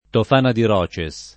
tof#na di reS], la Tofana di Mezzo [tof#na di m$zzo], la Tofana di Fuori [